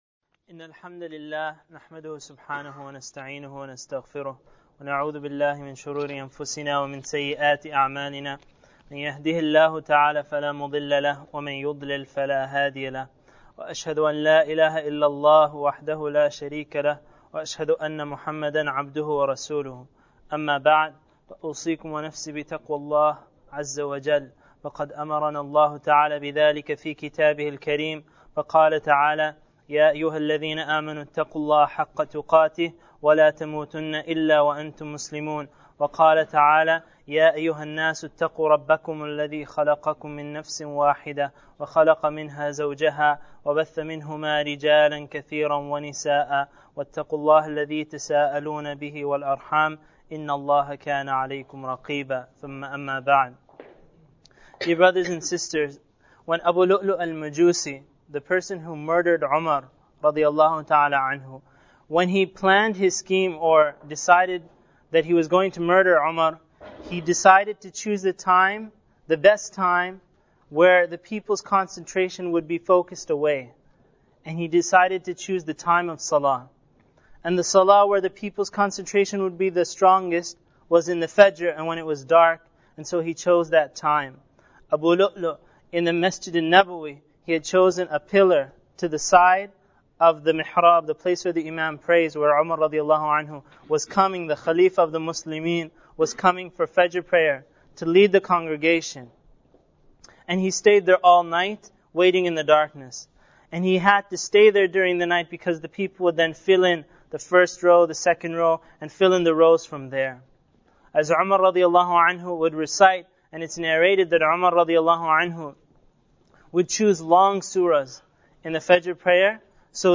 A lecture in English in which the lecturer explains the virtue of prayer and narrates the story of t